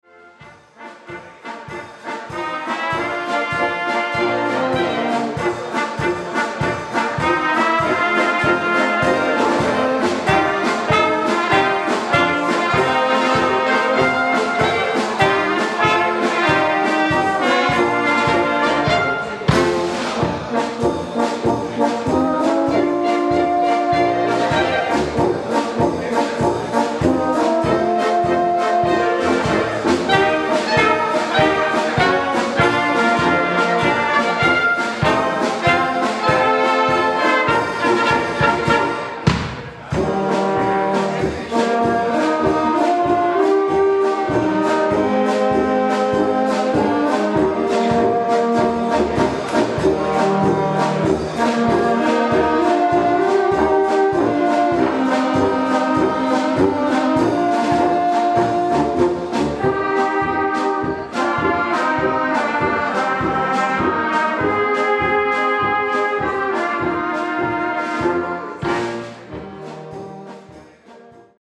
Polka für Blasmusik…